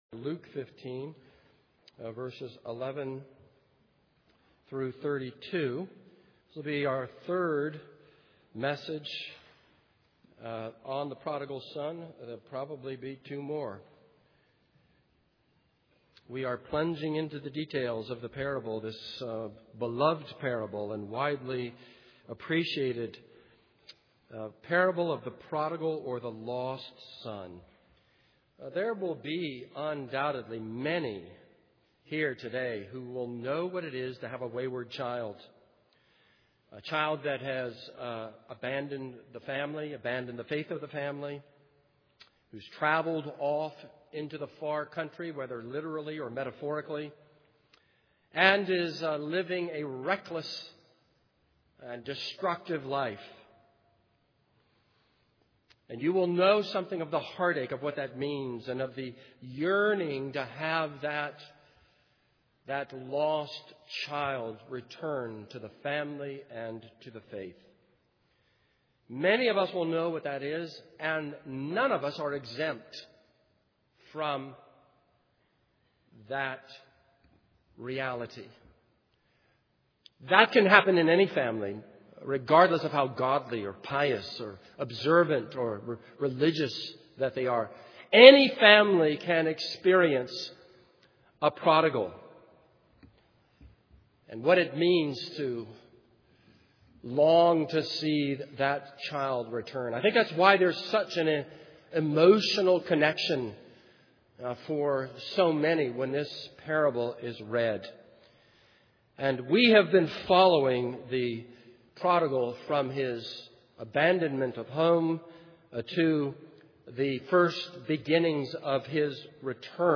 This is a sermon on Luke 15:11-32.